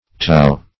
tau - definition of tau - synonyms, pronunciation, spelling from Free Dictionary
Tau \Tau\ (tou), n. [Gr. tay^.]